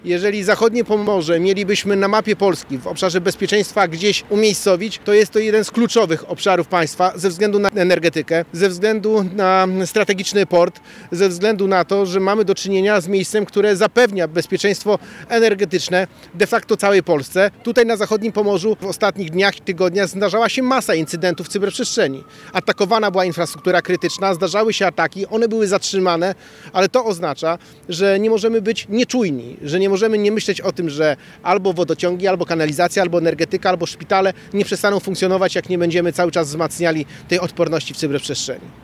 Więcej policjantów, nowe schrony oraz lepsza ochrona przed cyberatakami – takie działania zapowieda rząd, aby wzmocnić bezpieczeństwo Pomorza Zachodniego. Podczas konferencji prasowej w Szczecinie głos zabrał wicepremier i minister cyfryzacji Krzysztof Gawkowski, który podkreślił strategiczne znaczenie regionu.